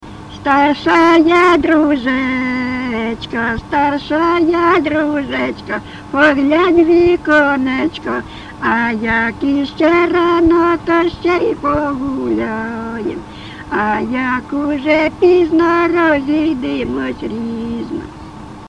ЖанрВесільні
Місце записус. Привілля, Словʼянський (Краматорський) район, Донецька обл., Україна, Слобожанщина